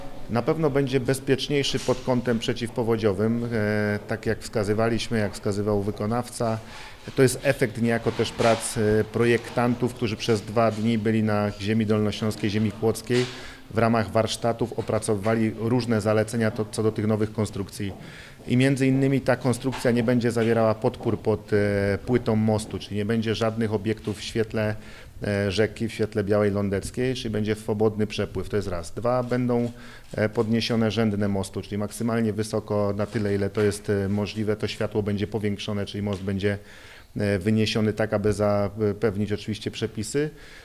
– Nowy most będzie bezpieczniejszy – mówi marszałek Województwa Dolnośląskiego Paweł Gancarz.